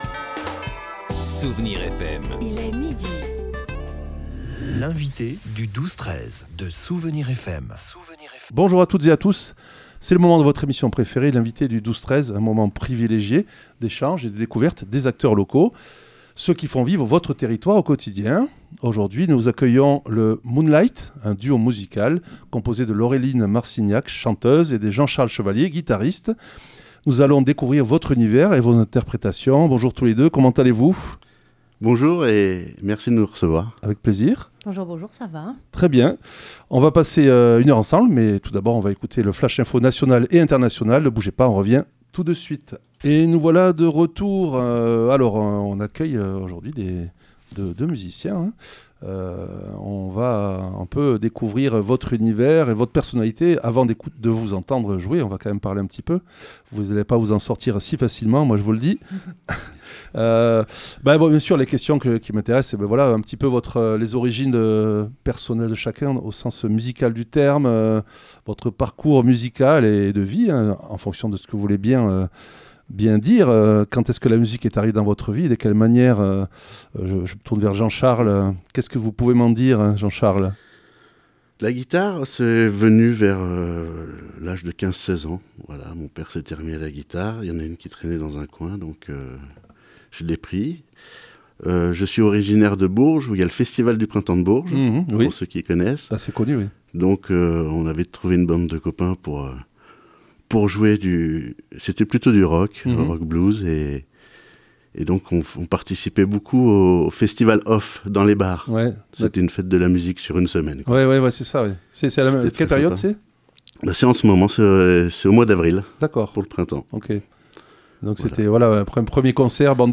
C'était au tour de Moonlight de venir ambiancer notre studio de Tosse !
Un superbe duo musical avec une guitare de velours et une voix de cristal....
On a parlé reprises musicales, répets et compagnie et surtout, nous avons eu la chance d'avoir quatre chansons interprétées en direct.